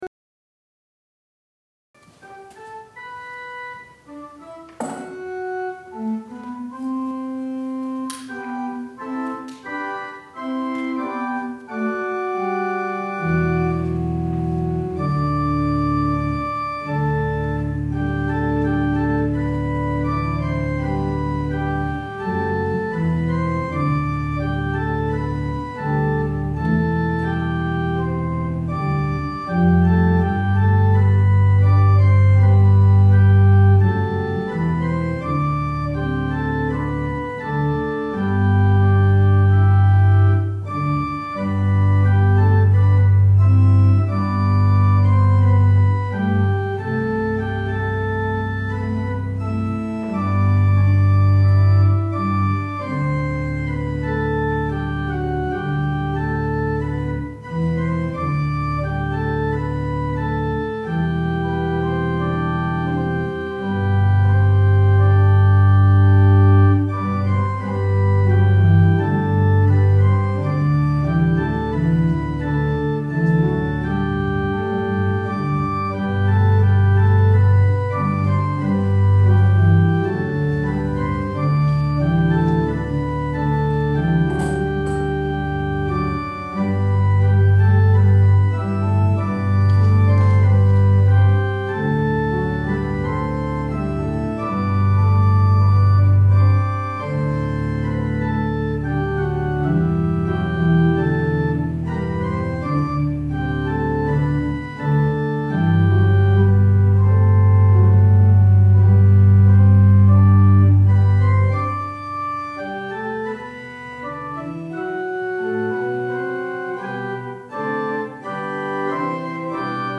The Humble King | Sermon for Palm Sunday
Worship Service for the Triumphal Entry of Our Lord